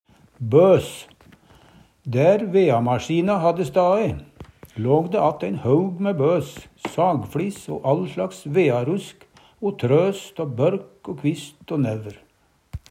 bøs - Numedalsmål (en-US)